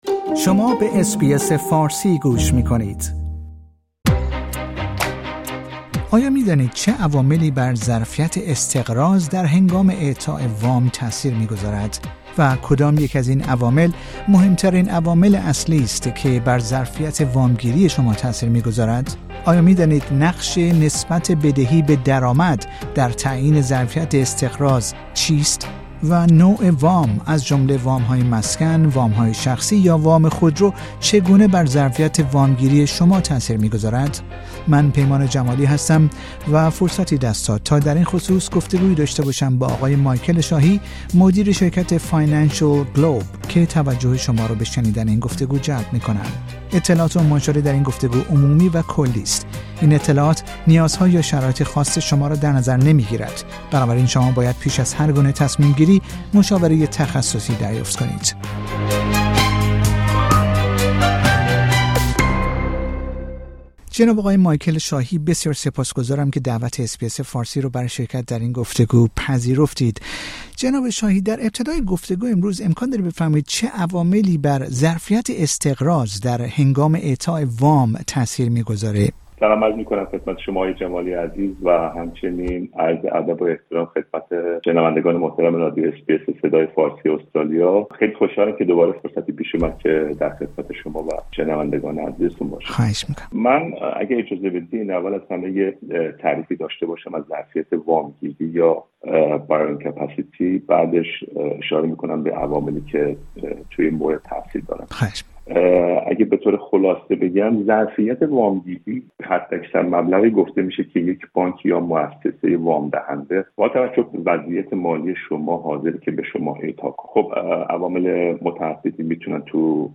در گفتگو با رادیو اس بی اس فارسی به پرسش ها پاسخ می دهد.